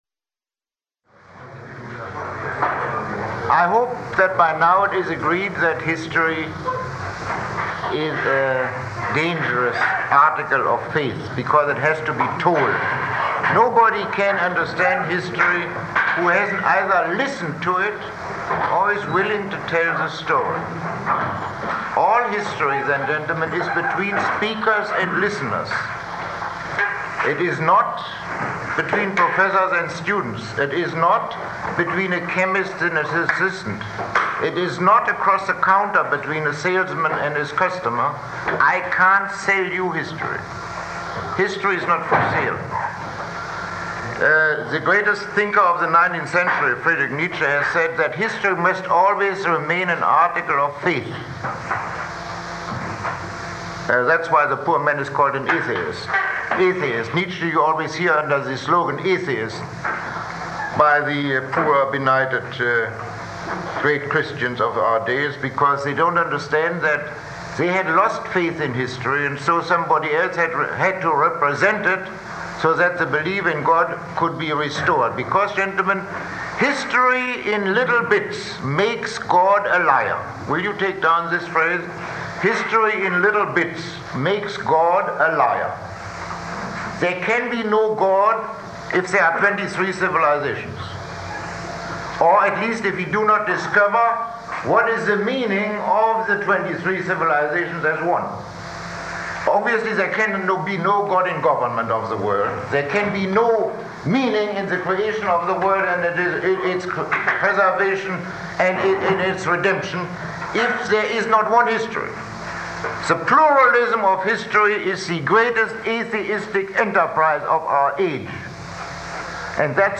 Lecture 02